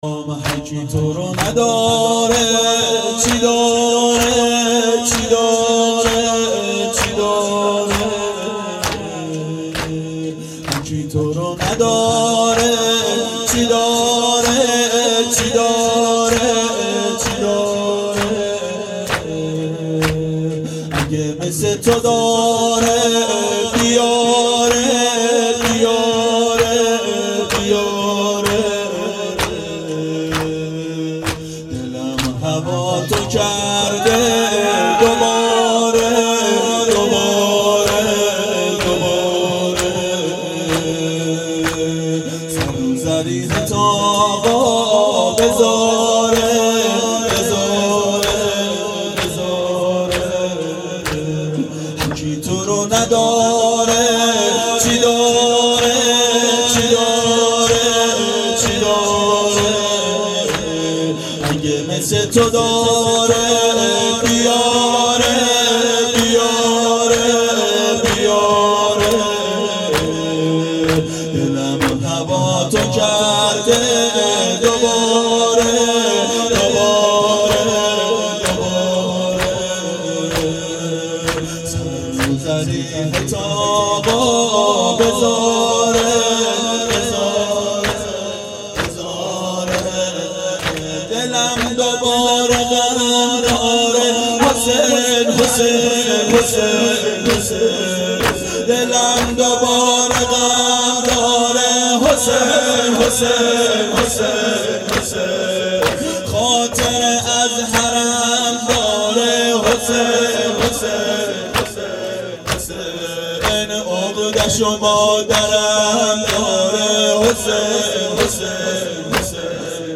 • شب سوم محرم 92 هیأت عاشقان اباالفضل علیه السلام منارجنبان